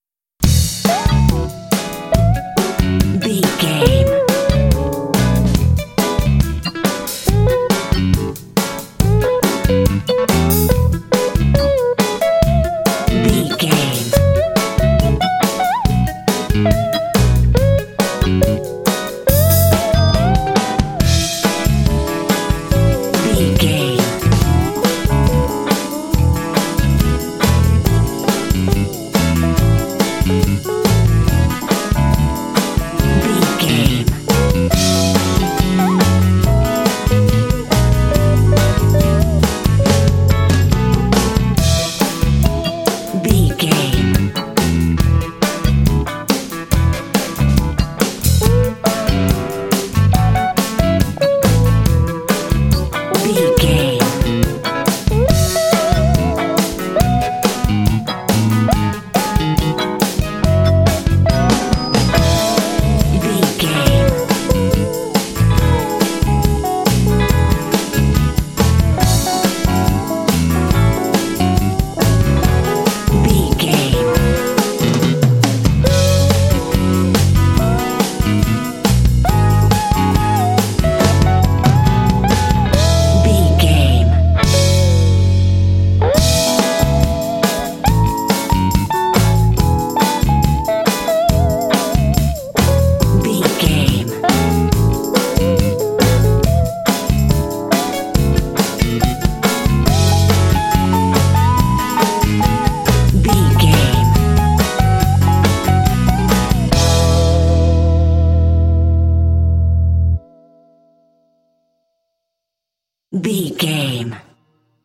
Uplifting
Ionian/Major
funky
groovy
electric guitar
bass guitar
drums
percussion
electric organ
electric piano
Funk
jazz
blues